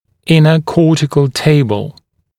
[‘ɪnə ‘kɔːtɪkl ‘teɪbl][‘инэ ‘ко:тикл ‘тэйбл]внутренняя кортикальная пластика